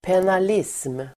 Ladda ner uttalet
Uttal: [penal'is:m]